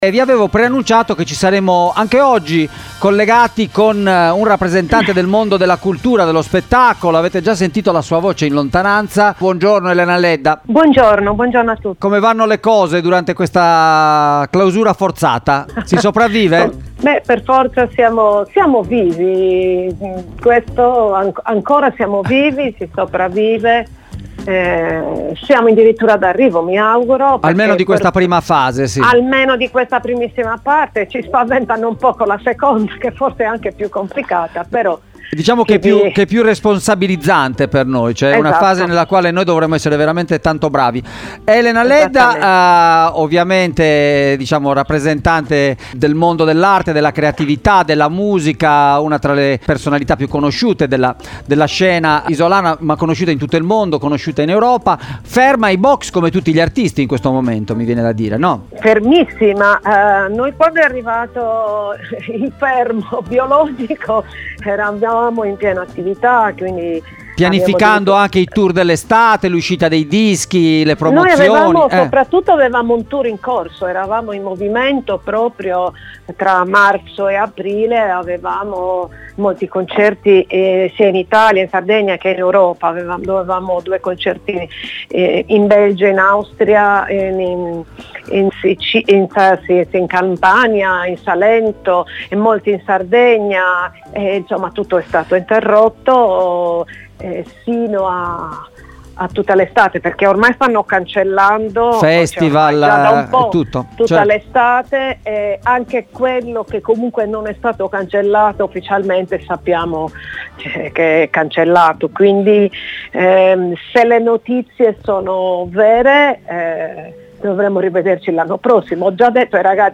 La nota cantante isolana, intervistata